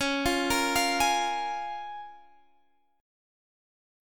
Db6 Chord
Listen to Db6 strummed